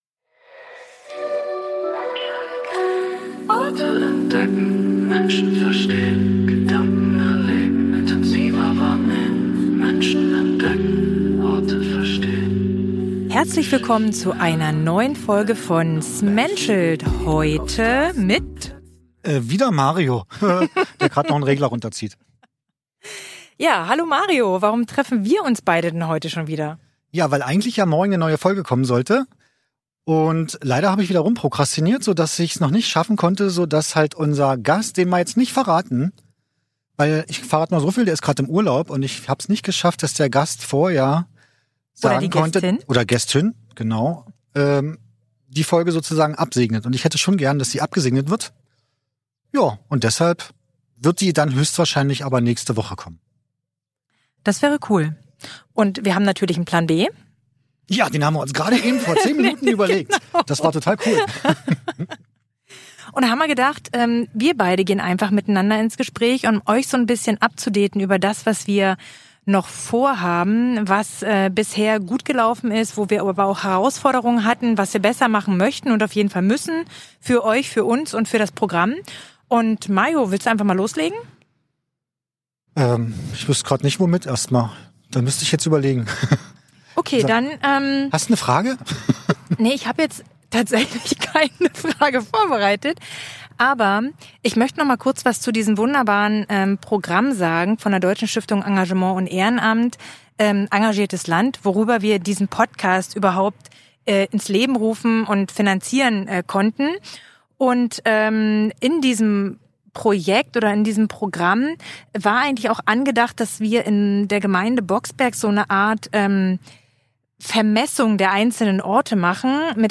Als Bonus gibt es ab Minute 22:47 eine KI-generierte Audioversion des aktuellen Amtsblatts - ein Experiment, das zeigt, wie Technologie lokale Information zugänglicher machen könnte. Eine spontane Folge über Projektentwicklung, technische Innovation und die praktischen Herausforderungen ehrenamtlicher Arbeit.